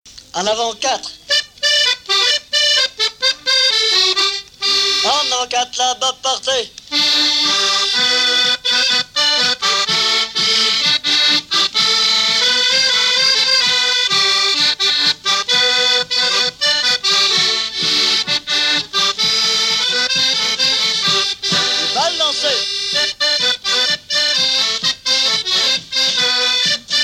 danse : quadrille : avant-quatre
Pièce musicale inédite